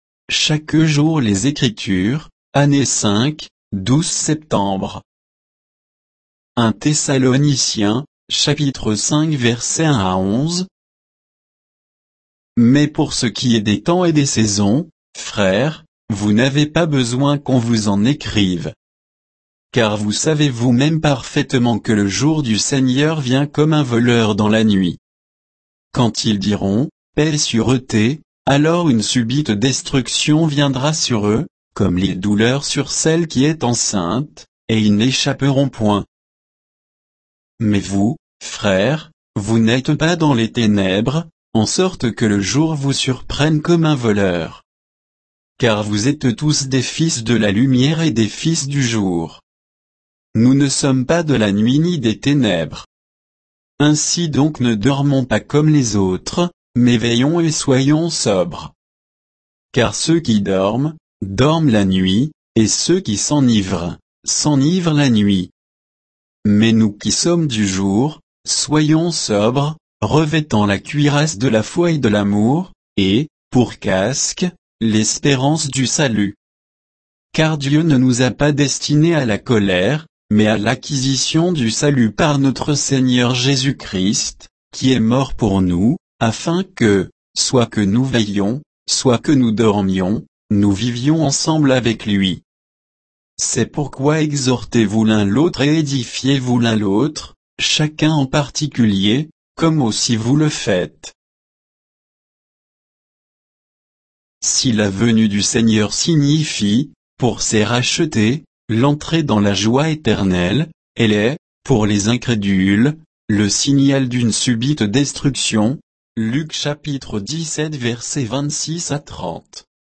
Méditation quoditienne de Chaque jour les Écritures sur 1 Thessaloniciens 5, 1 à 11